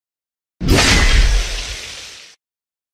Axe sound effects